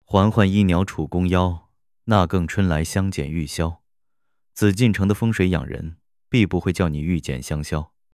智东西实测，用霸道总裁的声线说甄嬛传中皇上的经典台词：